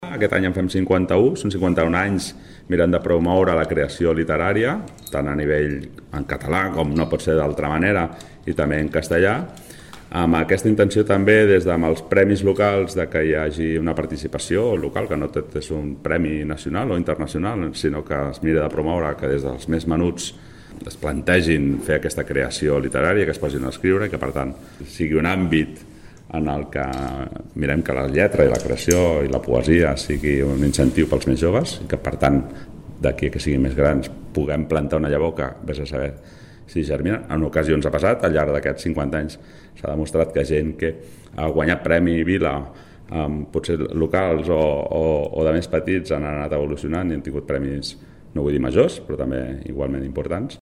Sergi Corral, regidor de Cultura de l'Ajuntament